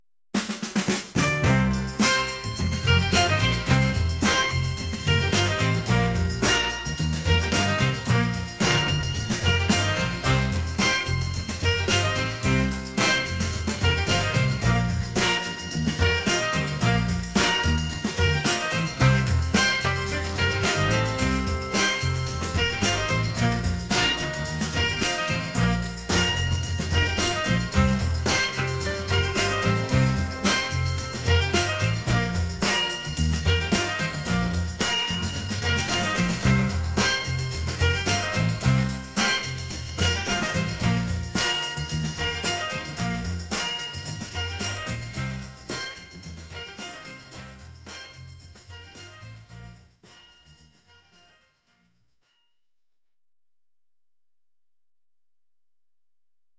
upbeat | soul